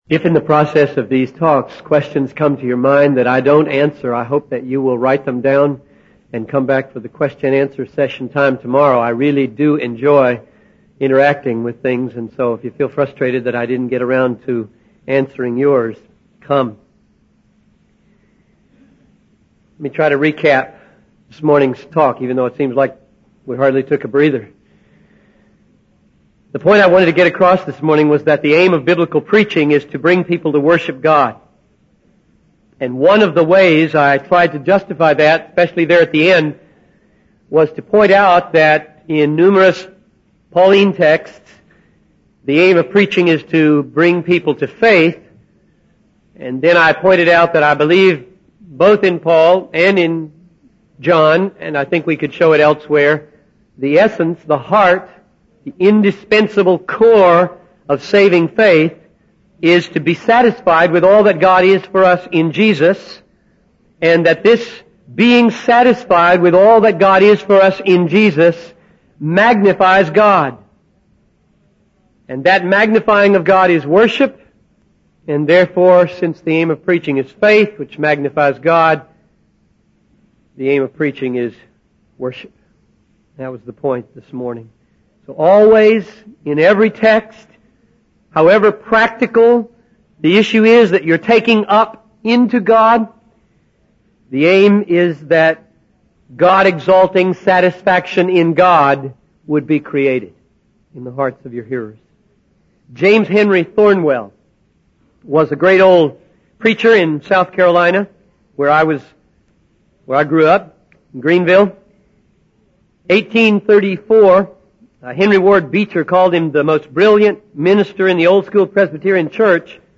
In this sermon, the preacher focuses on Matthew 13:44, where Jesus compares the kingdom of heaven to a hidden treasure in a field. The preacher emphasizes the importance of directing the attention of the listeners to the text of God's word and not elevating their own words above it.